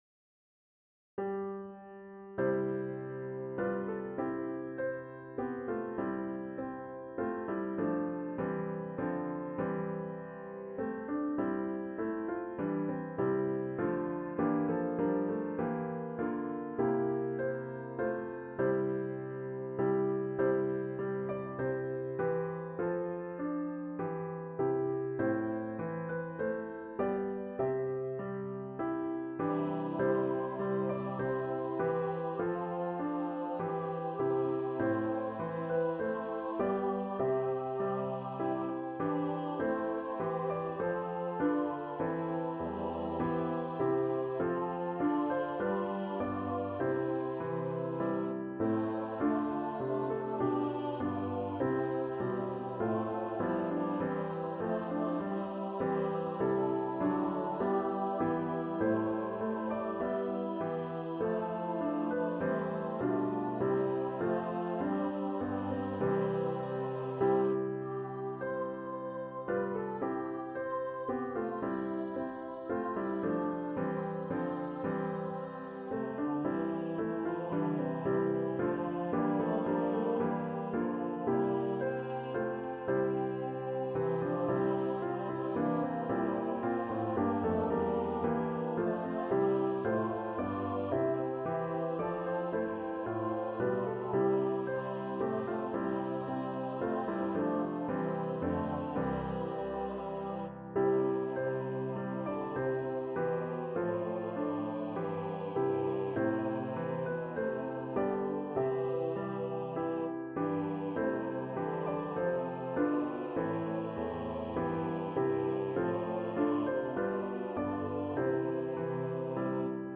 A choir anthem
traditional Irish melodies by Úna ní Ógáin